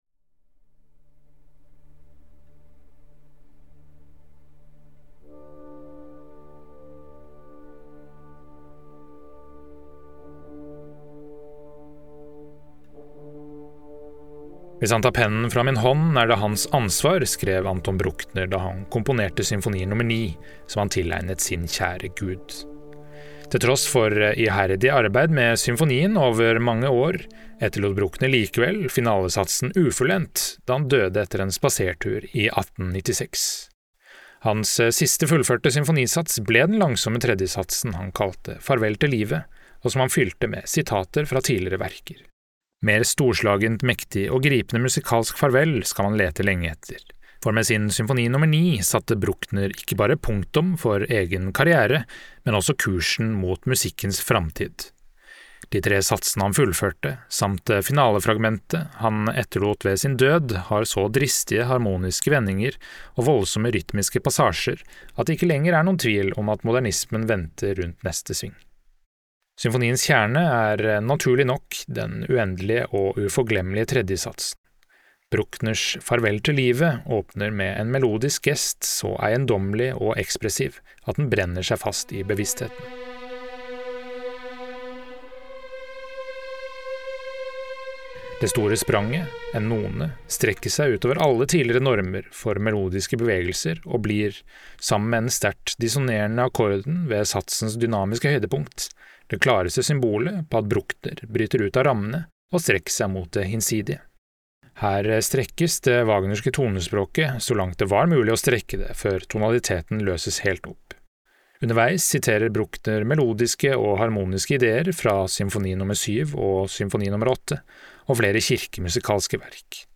VERKOMTALE-Anton-Bruckners-Symfoni-nr.-9.mp3